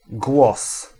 Ääntäminen
France: IPA: /vwa/